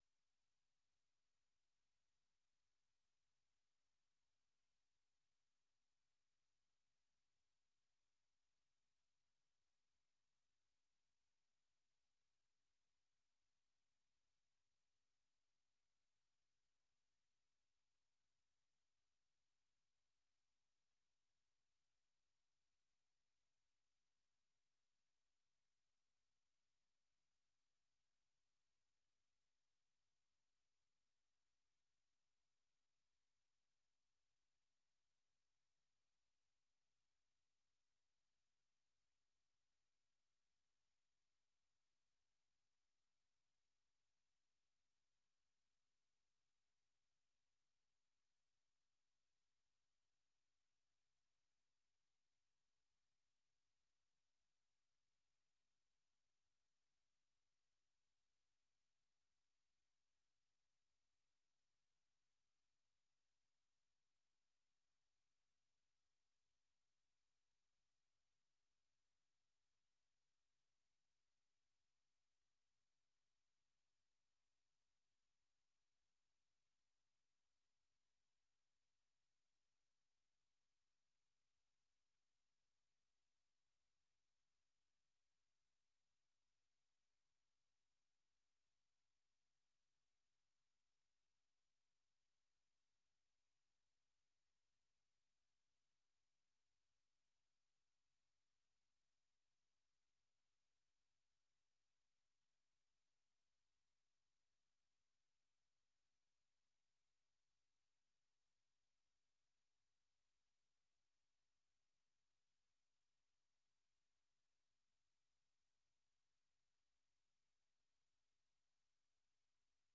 Un noticiero con información diaria de Estados Unidos y el mundo.